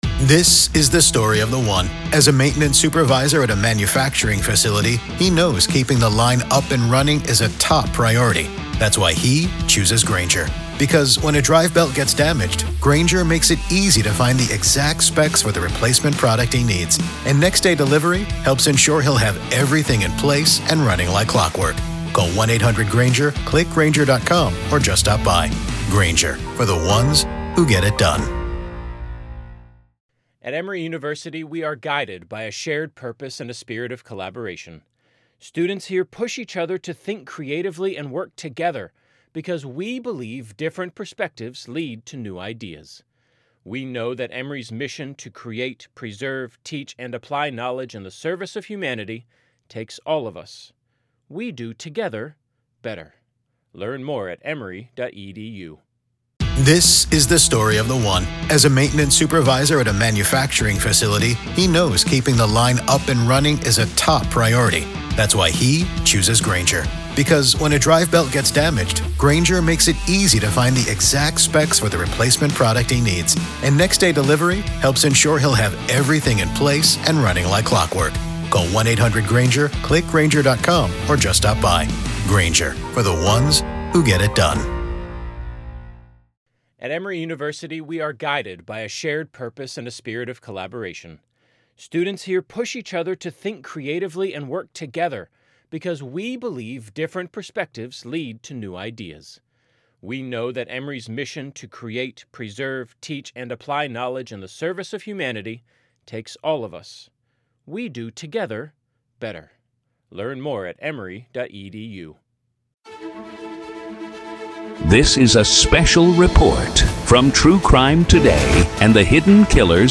Preliminary Hearing